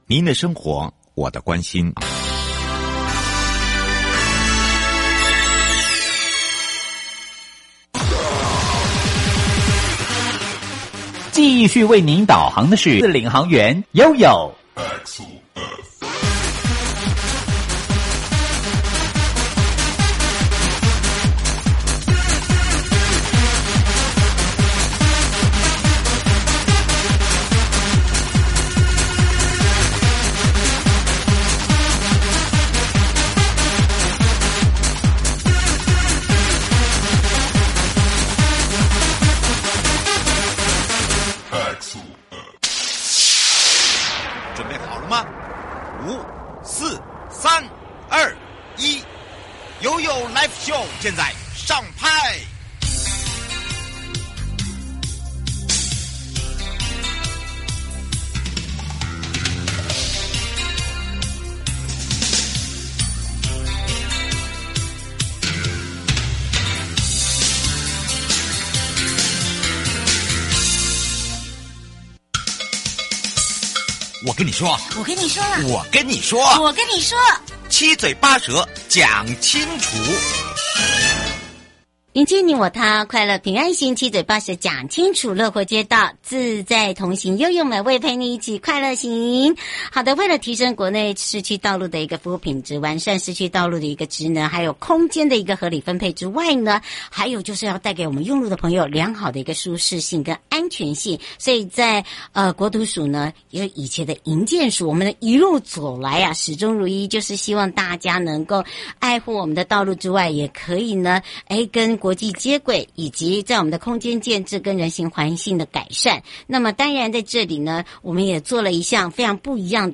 受訪者： 營建你我他 快樂平安行~七嘴八舌講清楚~樂活街道自在同行! 主題：#111年前瞻基礎建設計畫-提升道